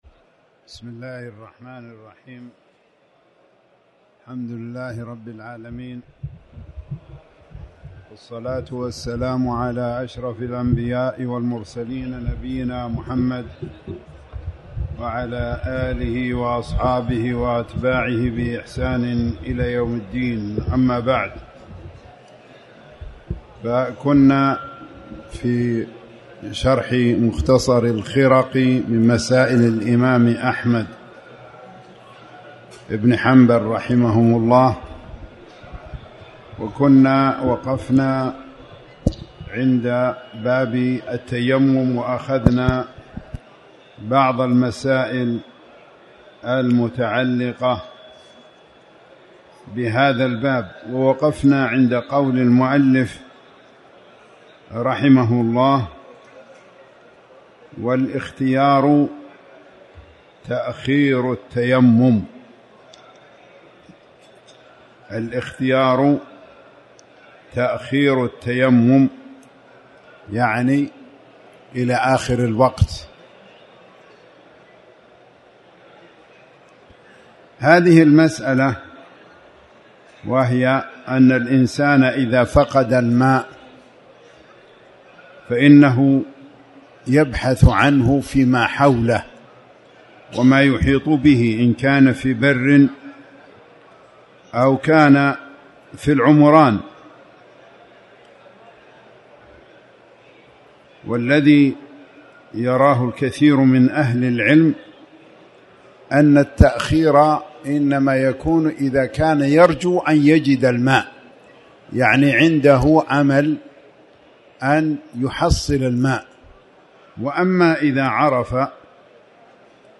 تاريخ النشر ١٣ شوال ١٤٣٩ هـ المكان: المسجد الحرام الشيخ